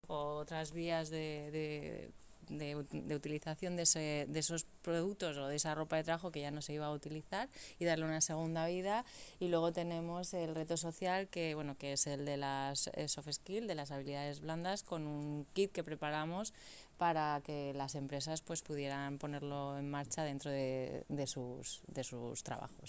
Corte de voz de la concejal de Empleo y Formación, Mari Carmen de España.
Mari-Carmen-de-Espana-con-los-resultados-del-Cilab-2024.mp3